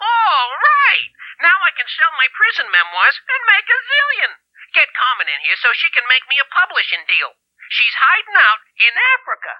- Added jail calls and portrait for Wonder Rat.